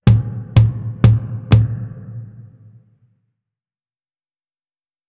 elefante.wav